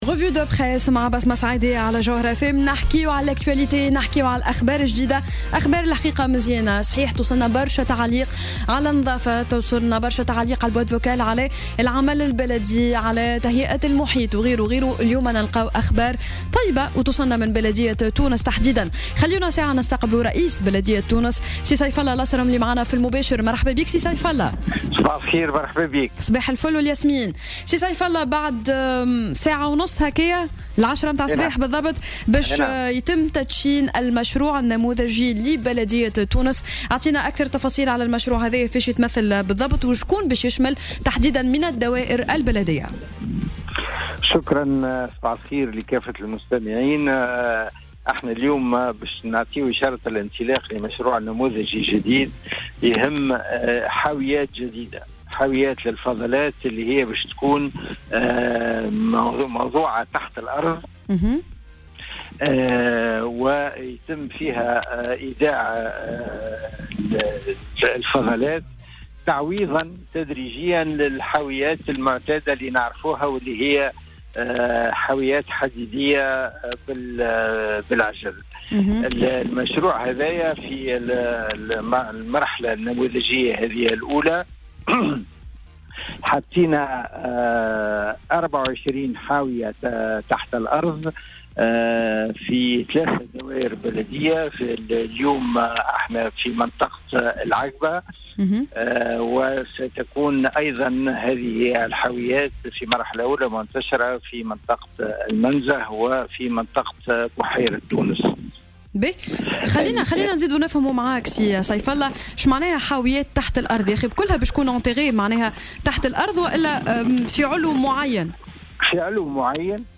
Lors de son intervention dans l'émission "Sbeh El Ward" ce mardi 11 avril 2017, le président de la municipalité de Tunis Seifallah Lasram a affirmé que le coup d'envoi de la mise en service des conteneurs à déchets semi-enterrés sera donné aujourd'hui.